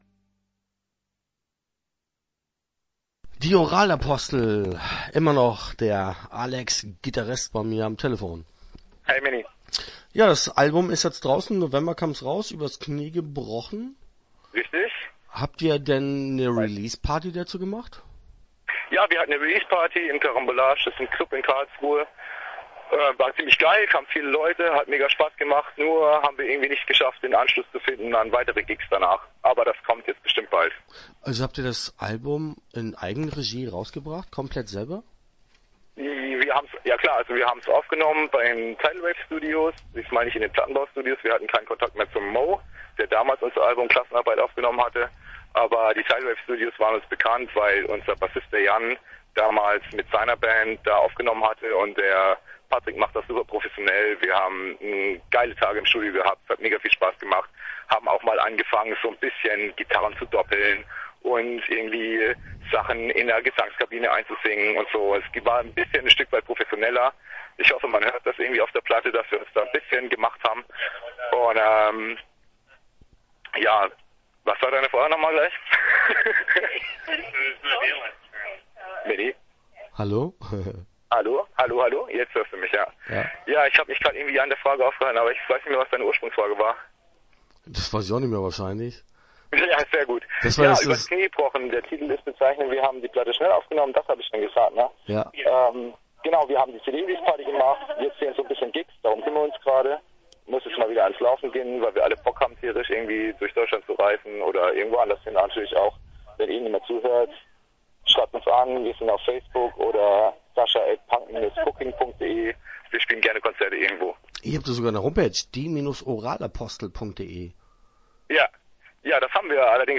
Die Oralapostel - Interview Teil 1 (7:31)